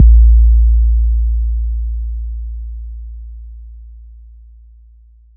Southside 808 (22).wav